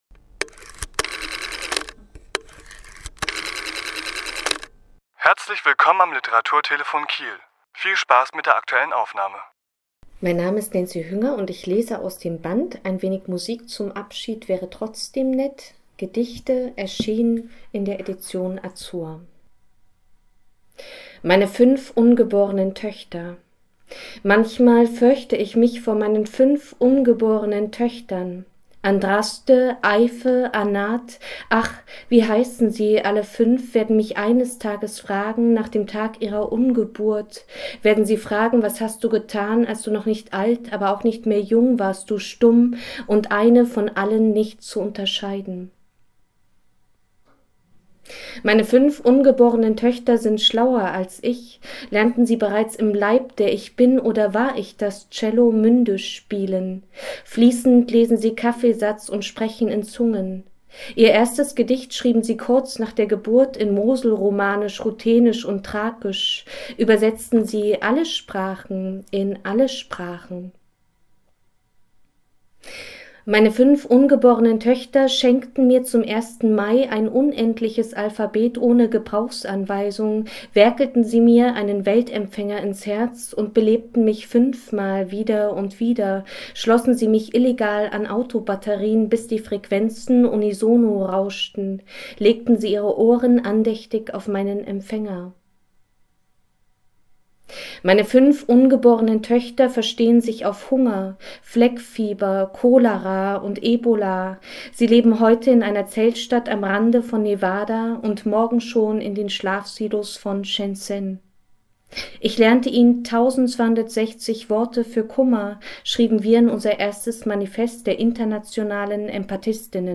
Autor*innen lesen aus ihren Werken
Die Aufnahme entstand im Rahmen einer Lesung in der Reihe Leselounge im Literaturhaus Schleswig-Holstein am 7.2.2018.